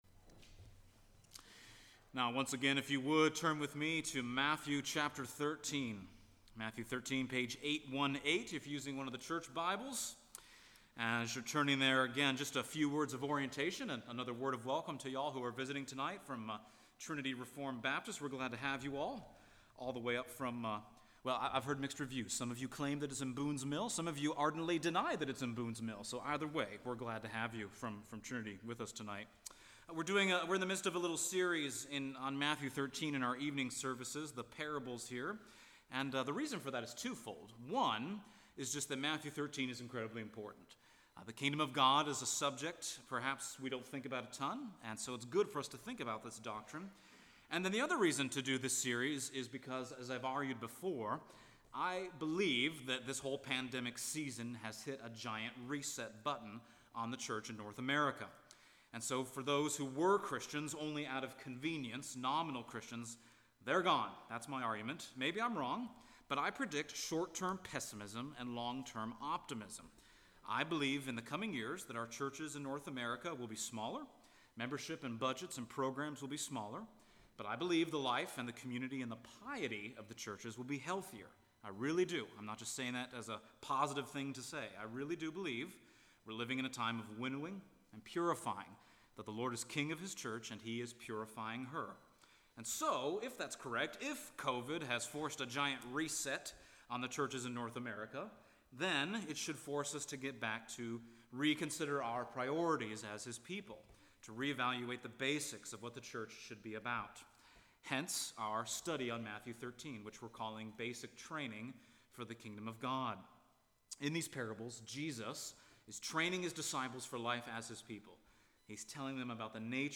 Matthew Passage: Matthew 13:1-52 Service Type: Sunday Evening %todo_render% « Basic Training